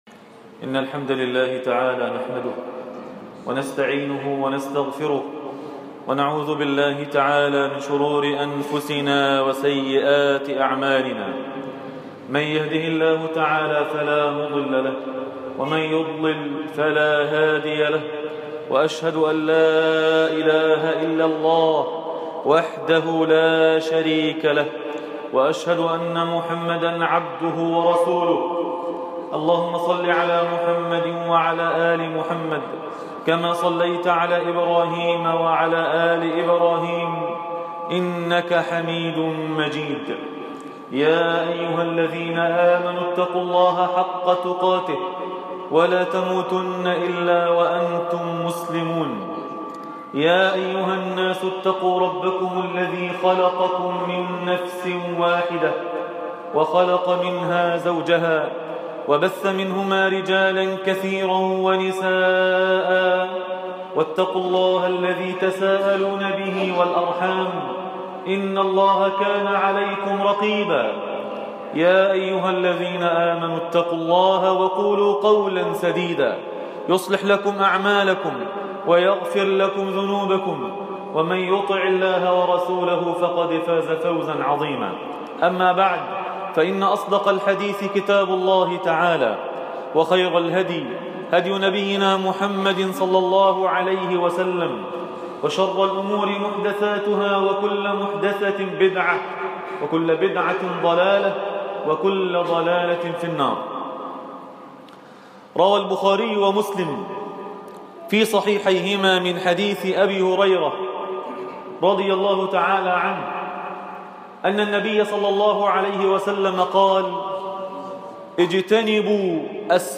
السبع المهلكات | خطبة جمعة